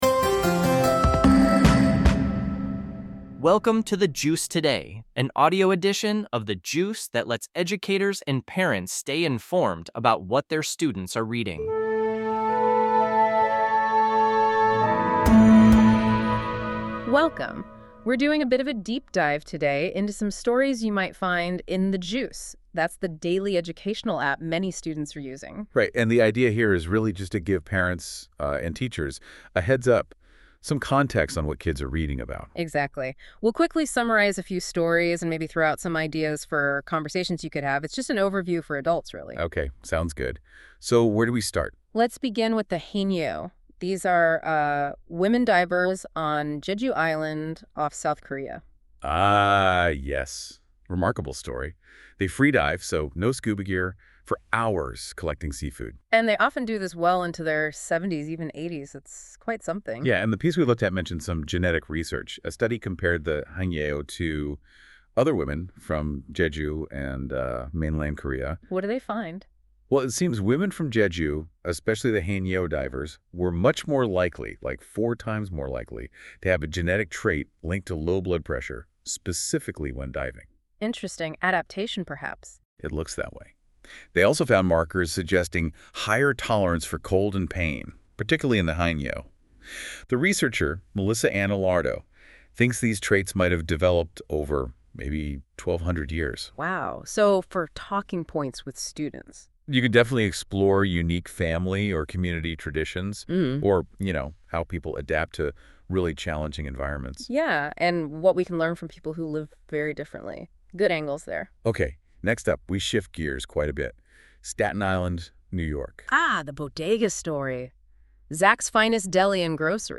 This podcast is produced by AI based on the content of a specific episode of The Juice.